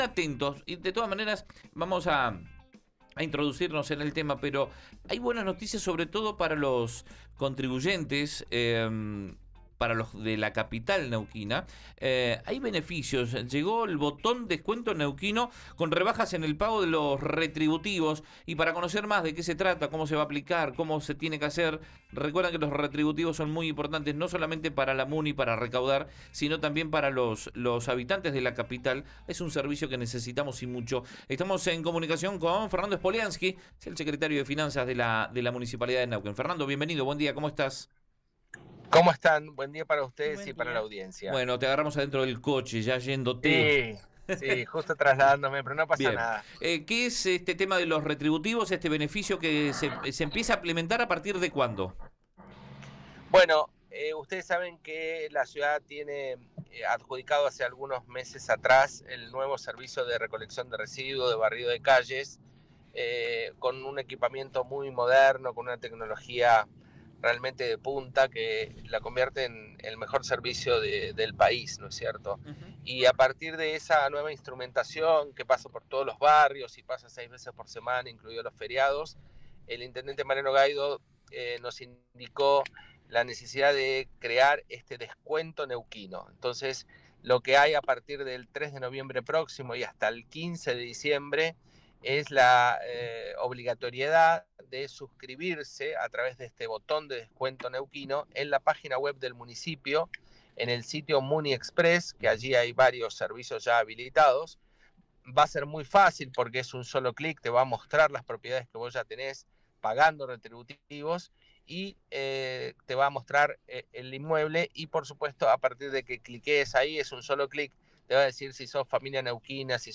Escuchá a Fernando Schpoliansky, el secretario de Finanzas de Neuquén por RN Radio: